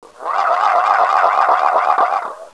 whirling.wav